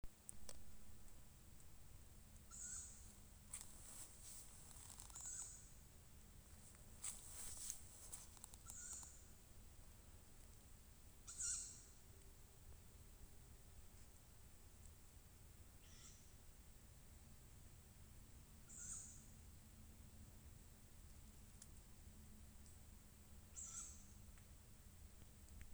Tawny Owl, Strix aluco
Count2
StatusRecently fledged young (nidicolous species) or downy young (nidifugous species)